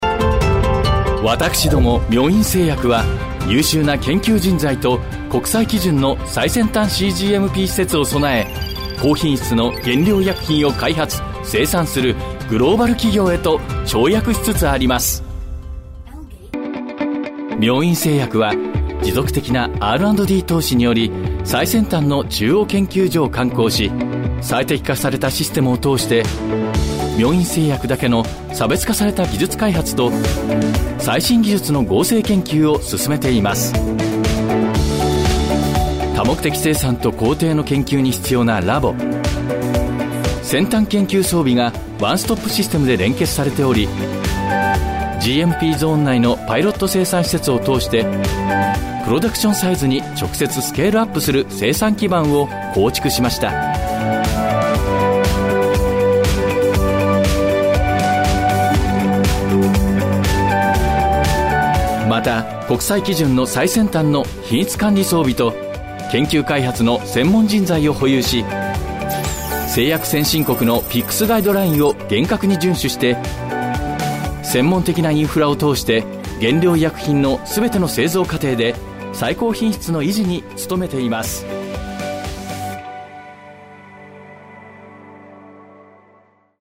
성우샘플
신뢰/묵직